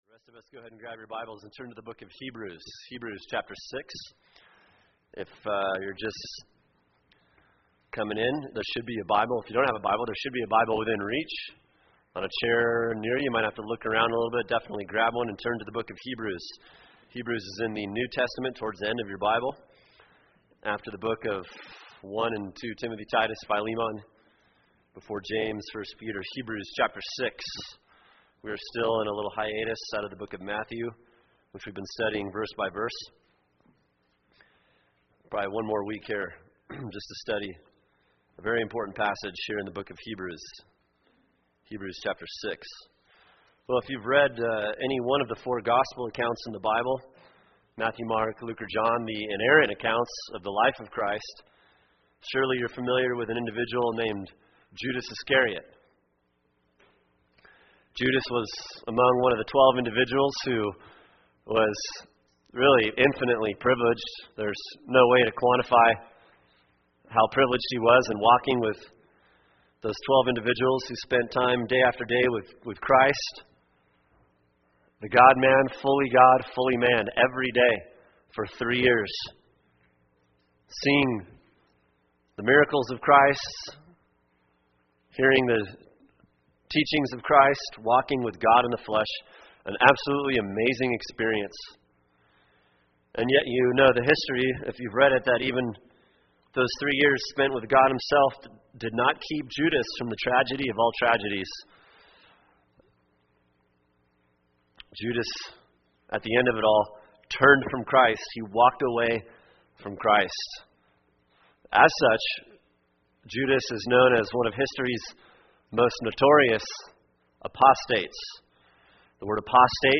[sermon] Hebrews 6:1-8 “The Tragedy of Apostasy” | Cornerstone Church - Jackson Hole